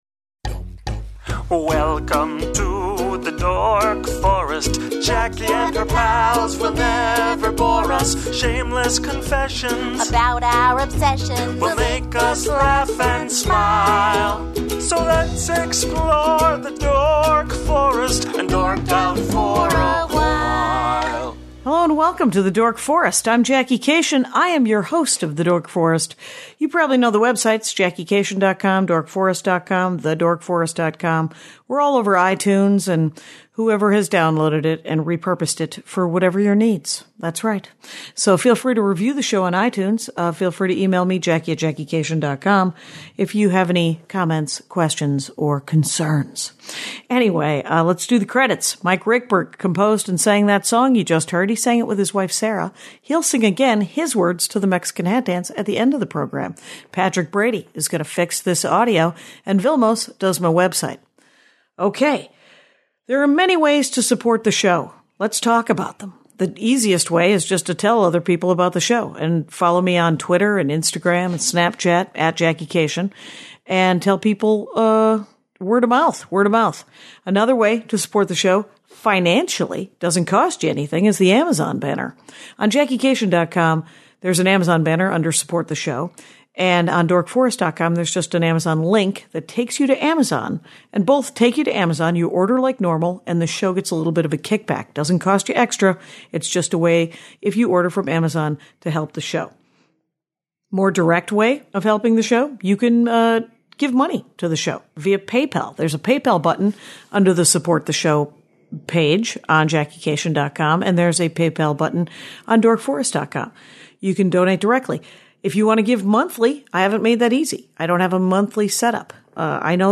We laugh a great deal.